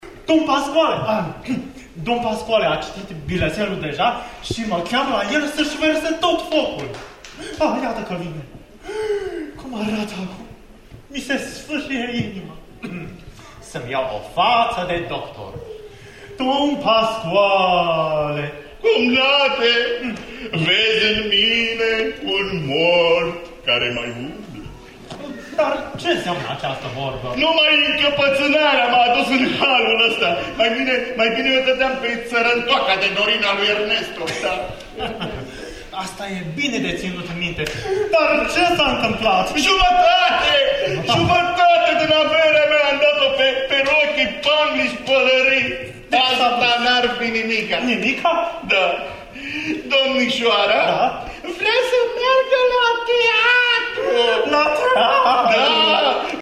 Opera Comică pentru Copii din Capitală a poposit la Sibiu pentru câteva ore, în cadrul festivalului Classics for Pleasure.
Cu râsetele unui public format atât din copii cât şi din oameni în toată firea pe fundal, Opera Comică pentru Copii din Bucureşti a oferit un spectacol de excepţie la finalul săptămânii trecute, în cadrul ediţiei speciale a Classics for Pleasure din acest an.
insert-dialog.mp3